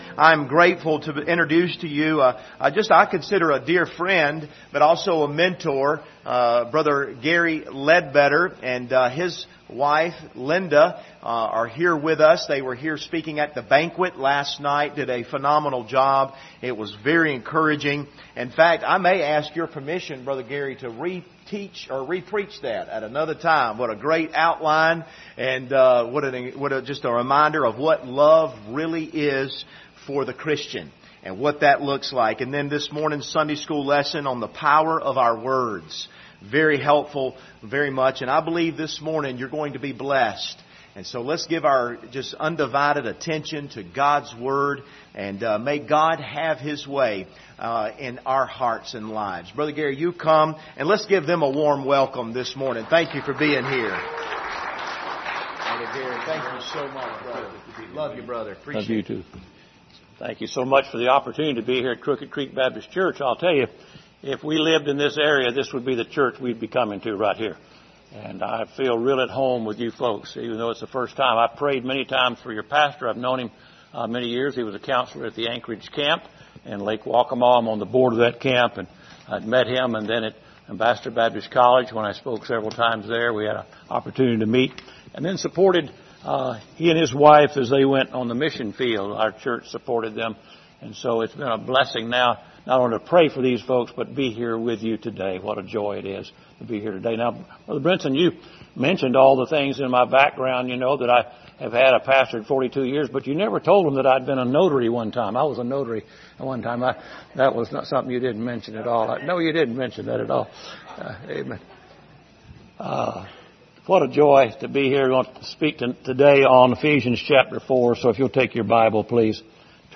Service Type: Sunday Morning Topics: forgiveness